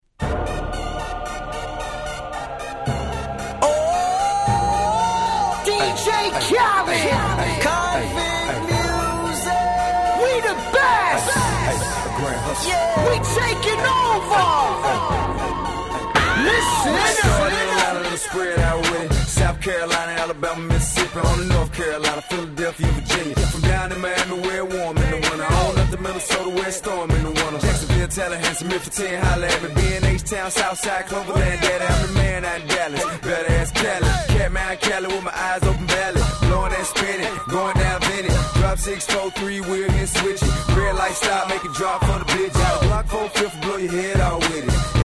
07' Big Hit Hip Hop !!